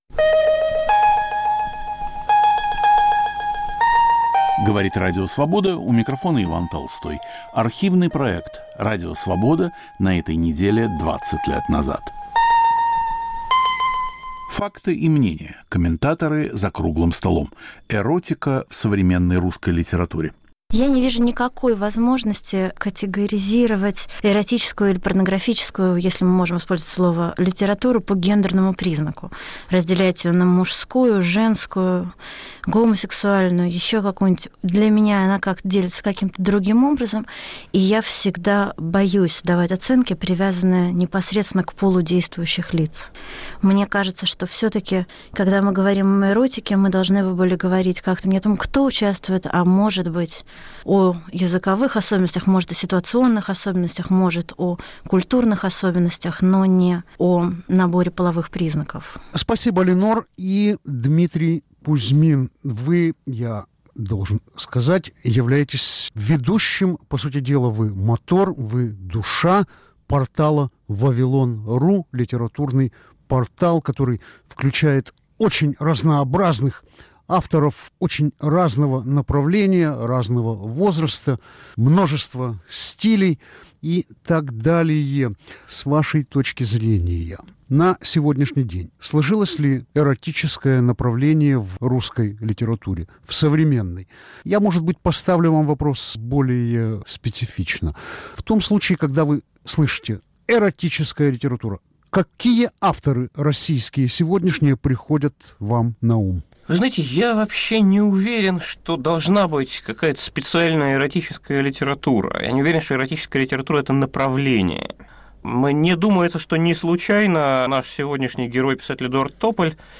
В студии Радио Свобода